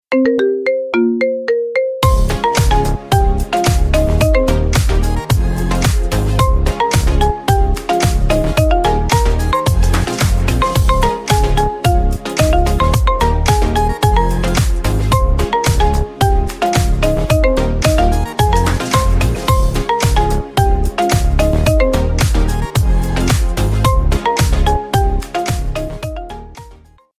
Рингтон